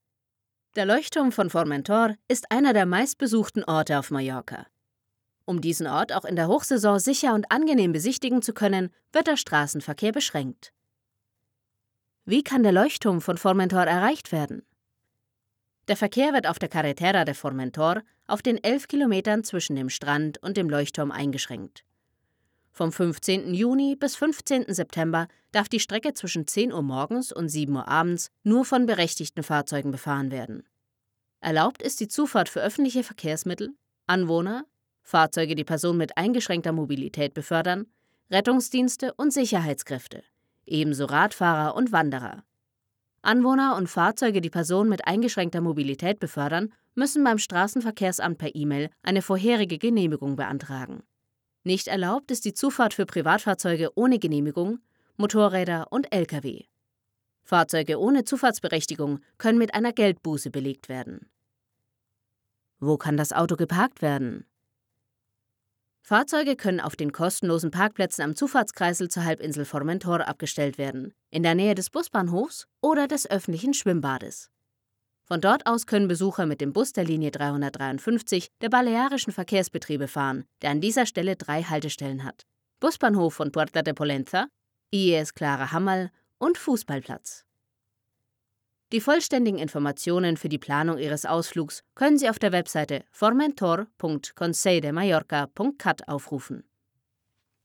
Im eigenen Studio nimmt sie dank Sennheiser MKH416 und Neumann TLM103 sauberes Audio in kurzer Zeit auf.
Fesselnde Stimme fĂŒr eLearning und ErklĂ€rvideos.
Sprechprobe: Industrie (Muttersprache):
Friendly and approachable sound suitable for image videos and ads.